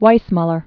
(wīsmŭlər, -mylər), Johnny 1904-1984.